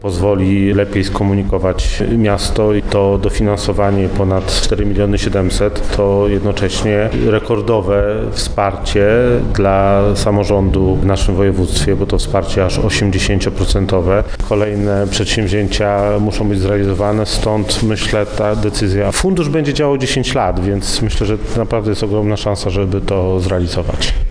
Fundusze przekazał Artur Chojecki, wojewoda warmińsko-mazurski.
-To maksymalne dofinansowanie, jakie przewidział ustawodawca – mówi wojewoda.